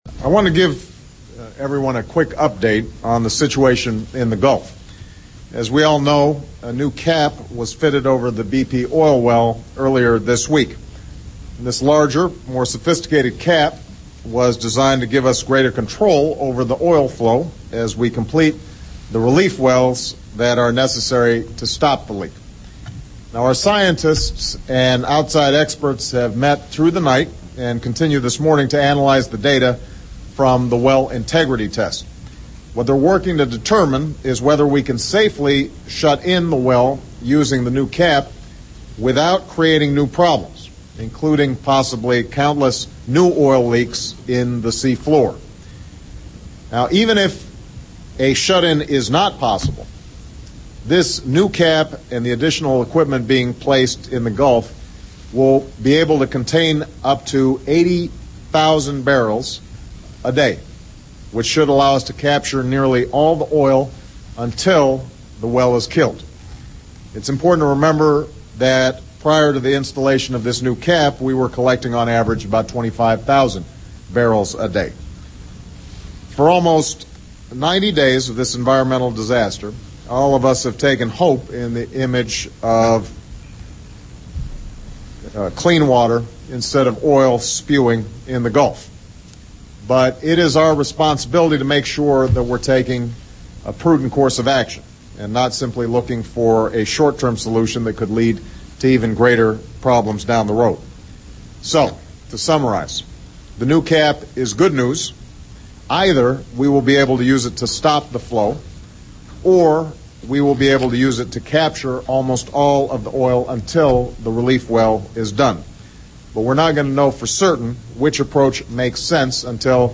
The Full speech of President Obama
obama_speech_oil_spill_16Jul10-st.mp3